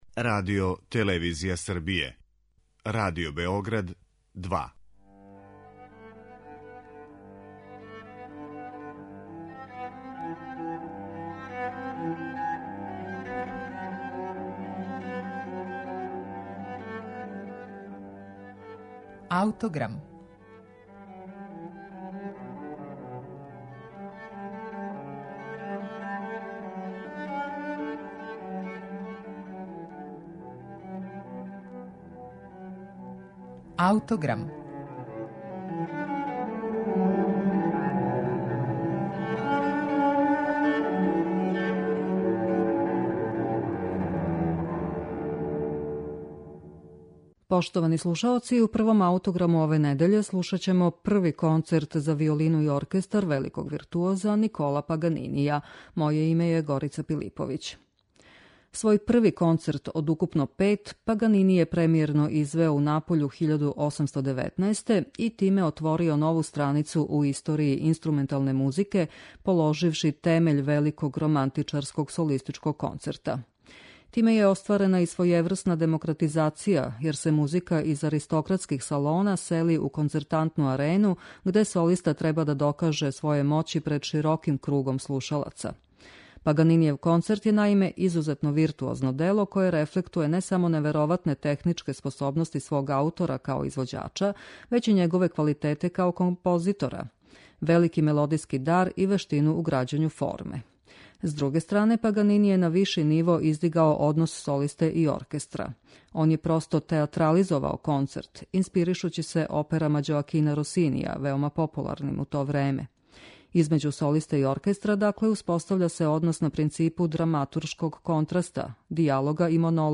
Николо Паганини: Први концерт за виолину и оркестар